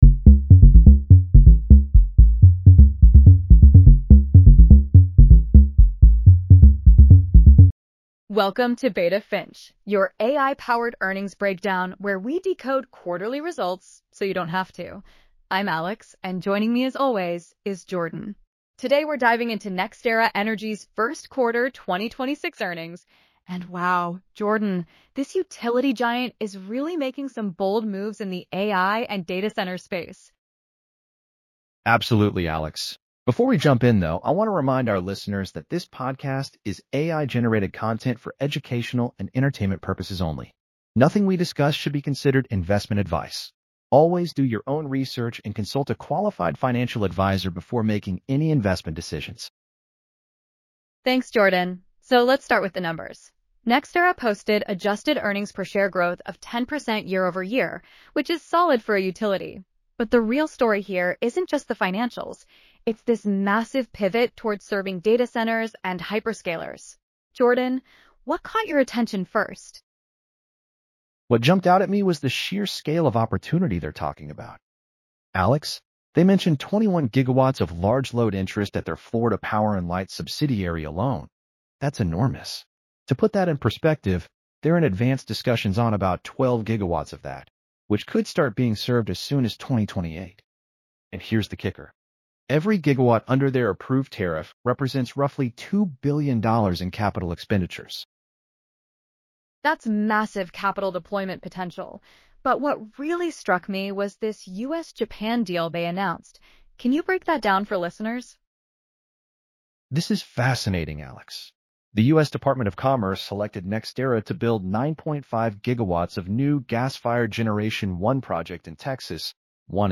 This is AI-generated content for educational purposes only.